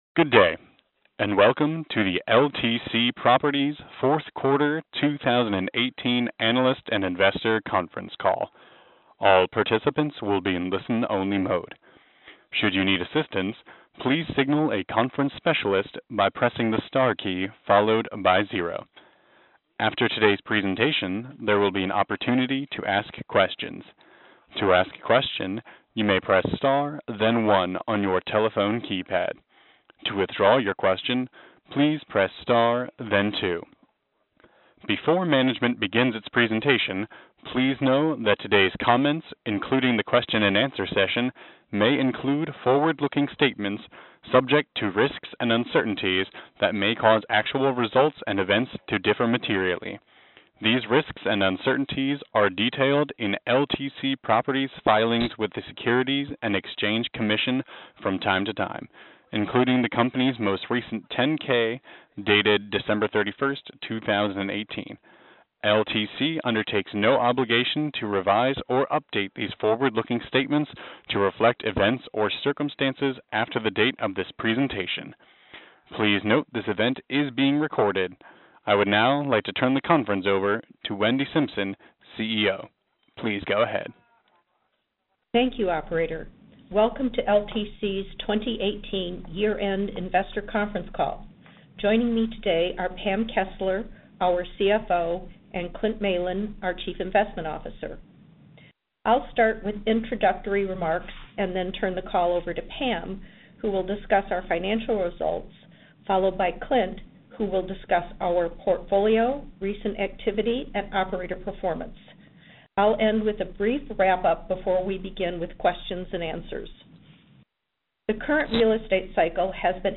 Earnings Webcast FY 2018 Audio
Audio-Replay-of-LTC-Properties-Inc-Q4-2018-Earnings-Call.mp3